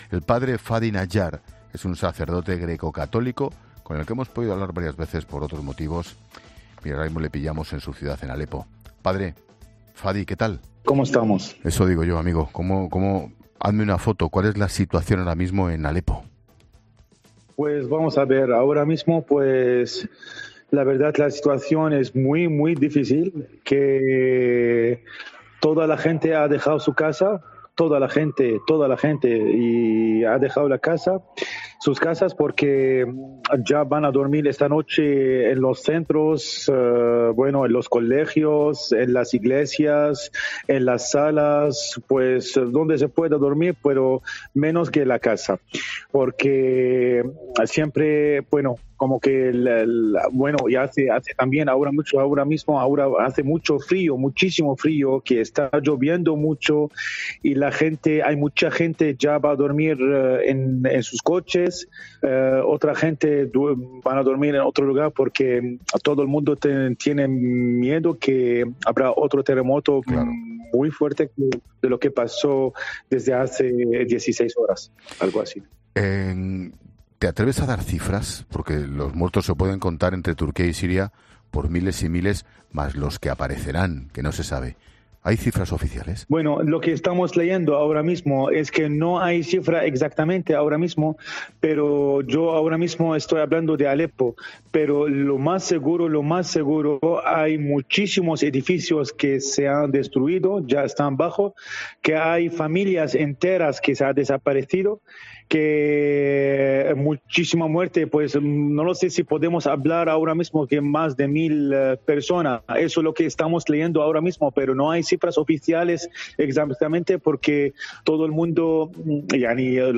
El sacerdote sirio atiende a La Linterna desde el epicentro del seísmo, que se estima que se ha cobrado más de 1.000 vidas